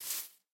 snapshot / assets / minecraft / sounds / step / grass4.ogg
grass4.ogg